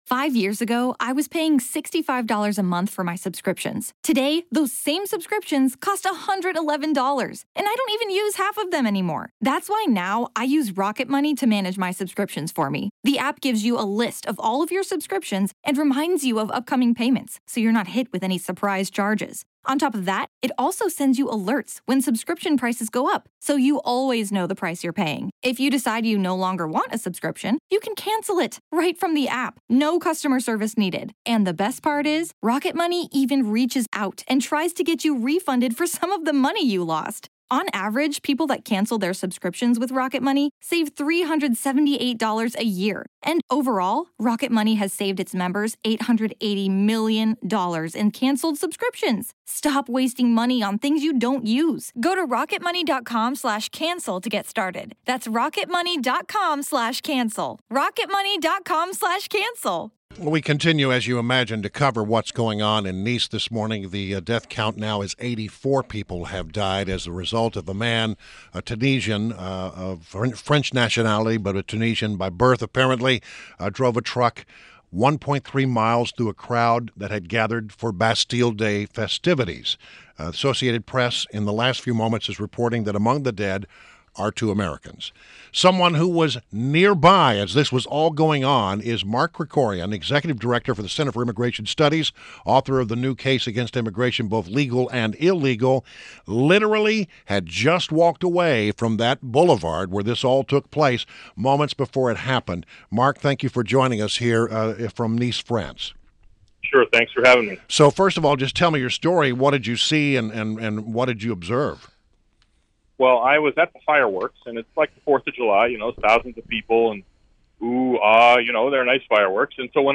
WMAL Interview - Mark Krikorian 07.15.16